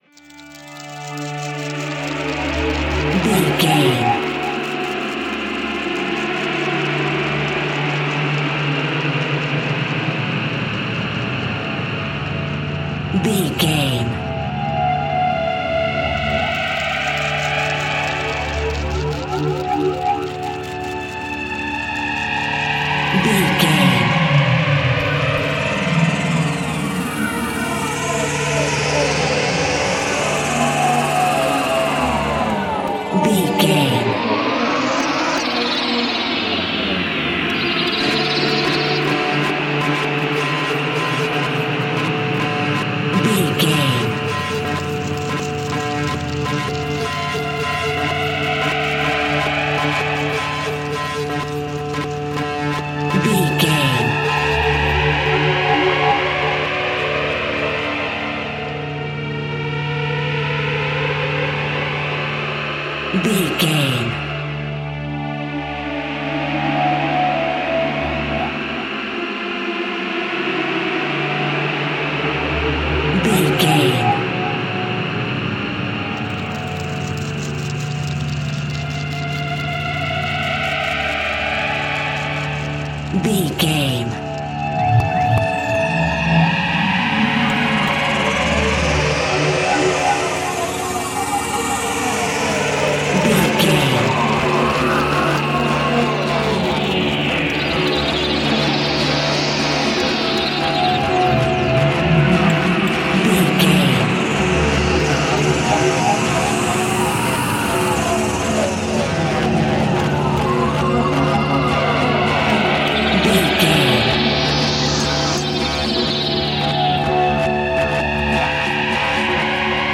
Aeolian/Minor
D
tension
ominous
dark
suspense
haunting
eerie
synthesizer
Horror synth
Horror Ambience
electronics